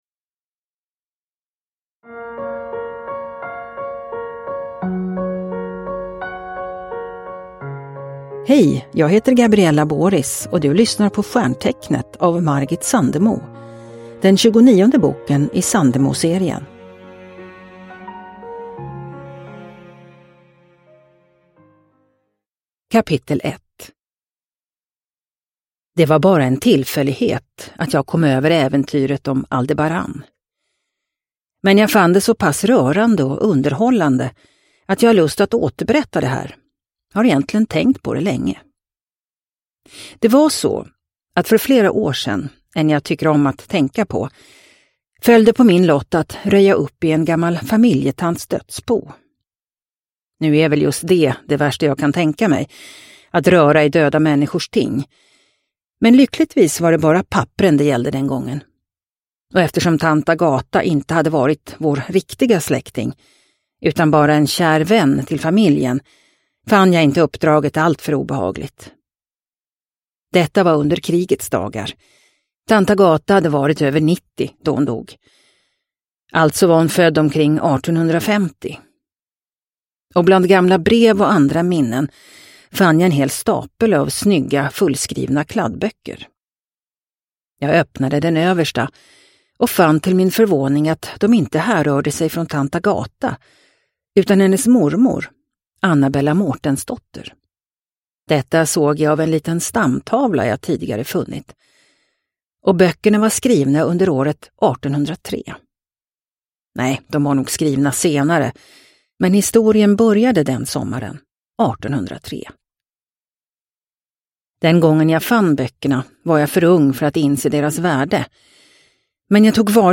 Stjärntecknet – Ljudbok – Laddas ner
Sandemoserien är en unik samling fristående romaner av Margit Sandemo, inlästa av några av våra starkaste kvinnliga röster.